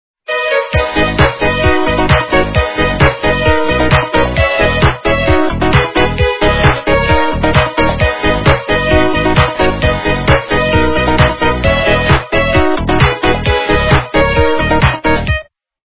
- русская эстрада
полифоническую мелодию